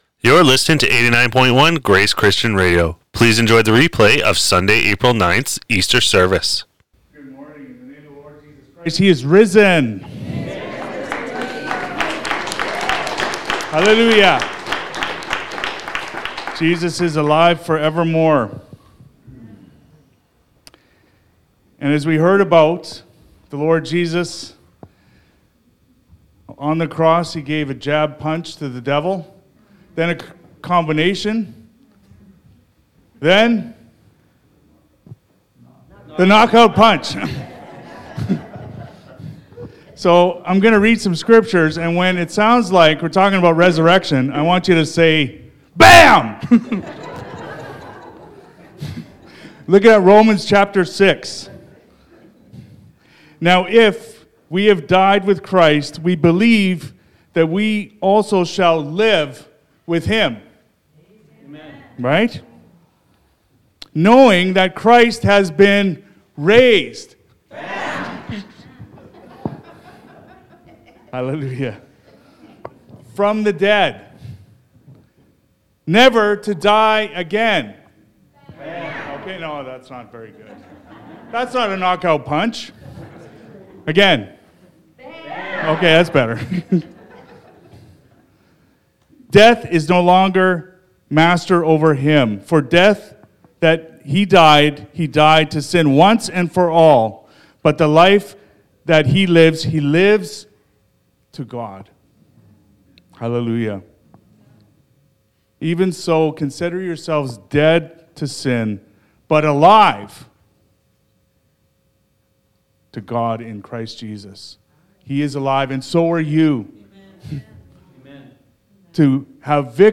Sermons | Grace Christian Fellowship
Rejoice! He is Risen! (Resurrection/Easter Service)